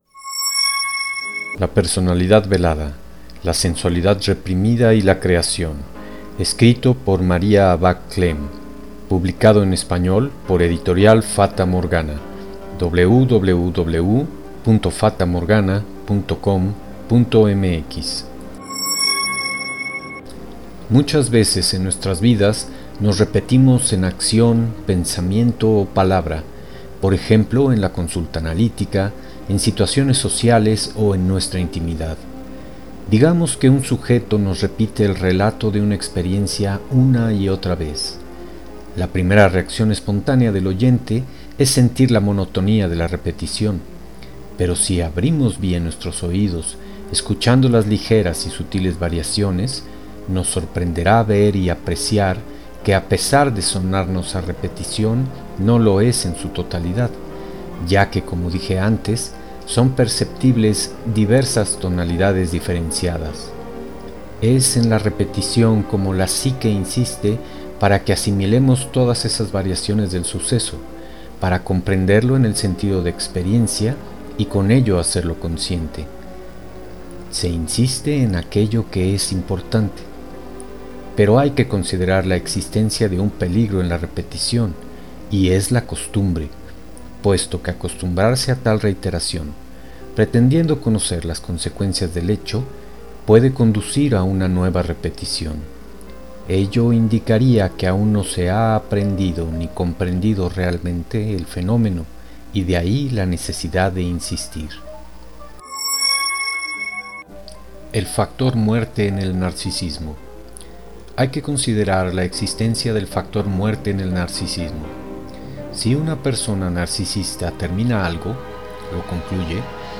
Lecturas de: La personalidad velada